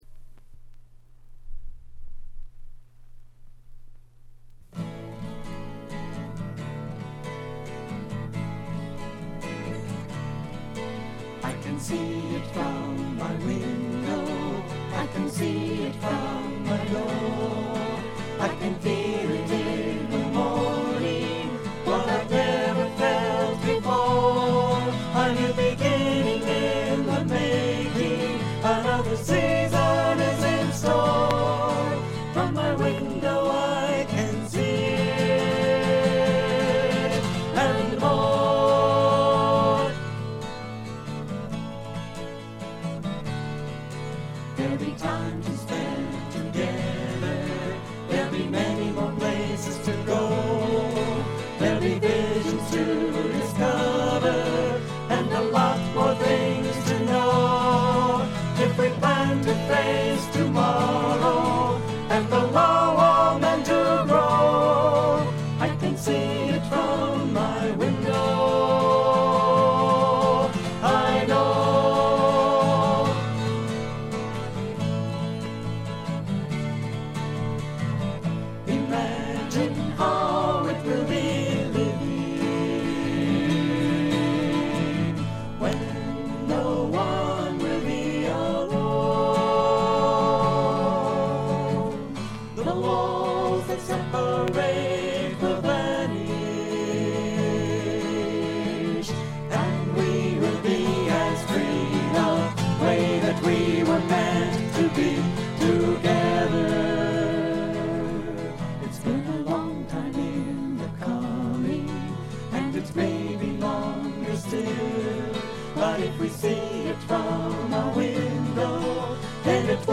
5箇所ほどで散発的なプツ音。
ウィスコンシンのクリスチャン系フォーク・グループでたぶんこれが唯一作の自主制作盤だと思います。
男女3人づつの6人組で、ほとんどがオリジナル曲をやっています。
試聴曲は現品からの取り込み音源です。